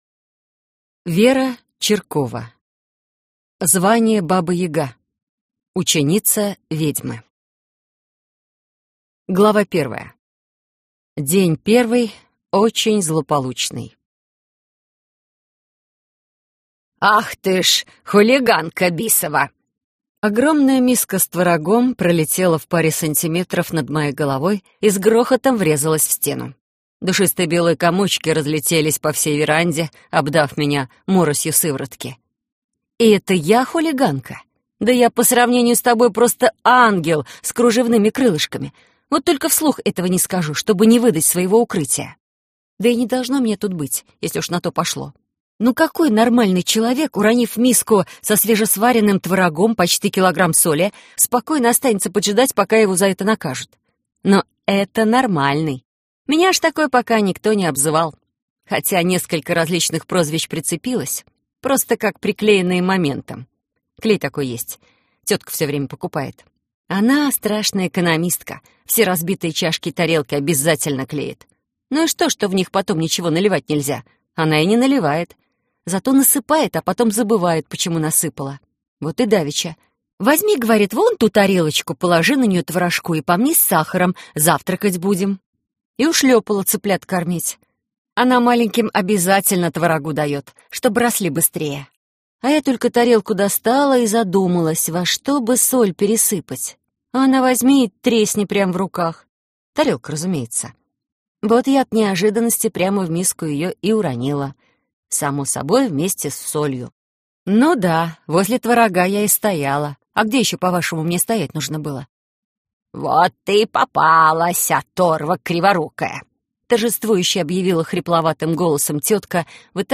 Аудиокнига Звание Баба-яга.